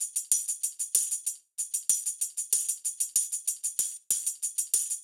SSF_TambProc1_95-02.wav